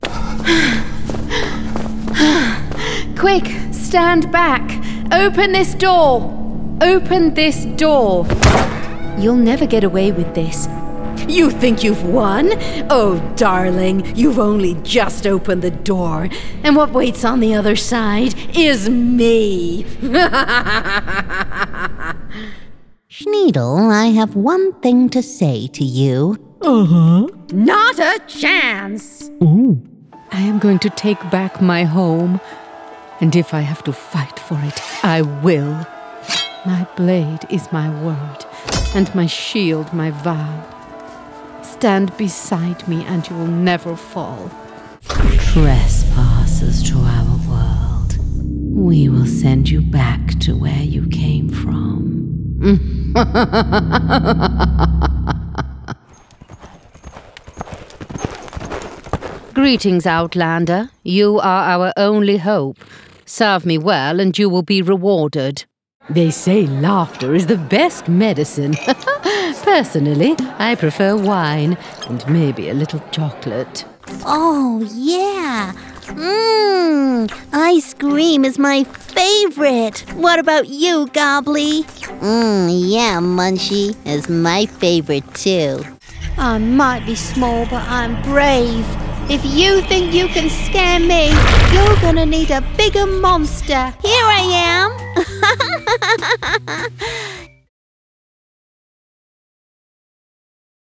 Inglês (britânico)
Videogames
Minha voz é natural e amigável, mas ressonante e autoritária. Meus clientes também adoram minha voz sedutora.
Conversacional, Amigável, Calorosa, Confiável, Acreditável, Articulada, Enérgica, Entusiasmada, Calmante, Corporativa, Profissional, Narradora, Suave, Sedutora, Mãe, Carinhosa, Informativa e Inteligente.
Microfone Audio Technica AT2020